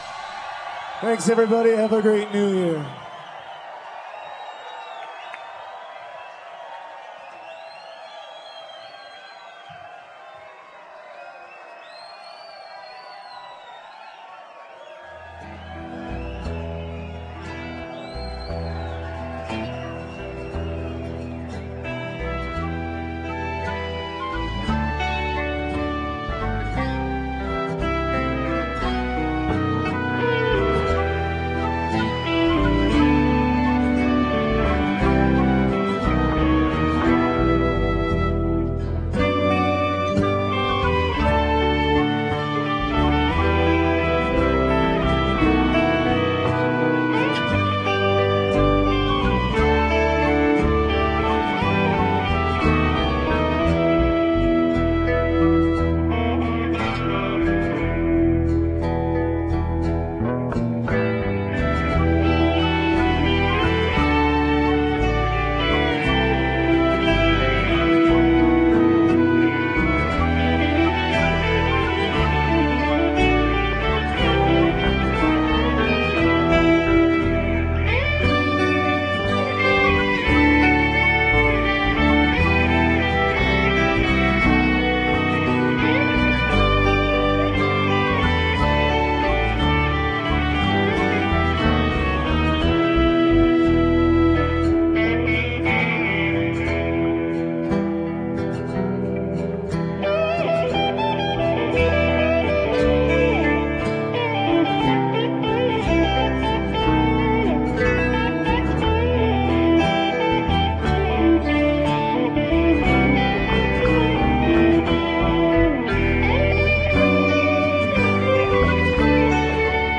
Filler: Mission> China Cat> I Know You RiderClosing Music: Greensleeves